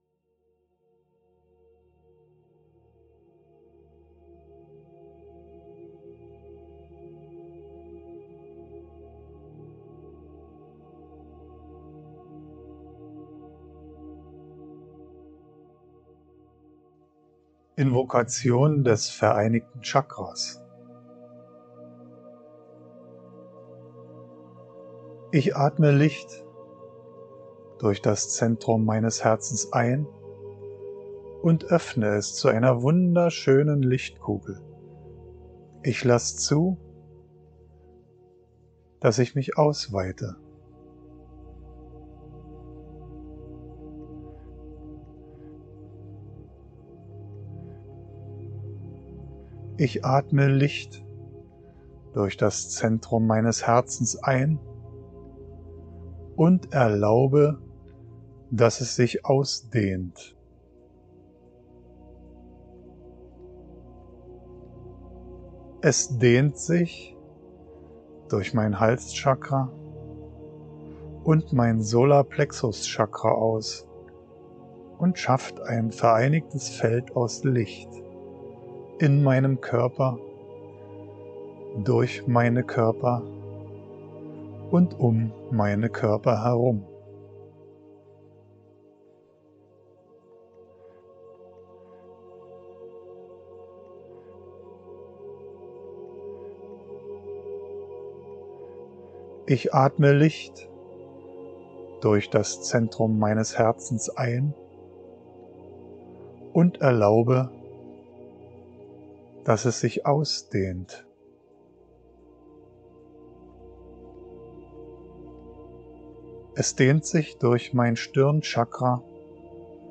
invokationvereinigteschakramitmusik.mp3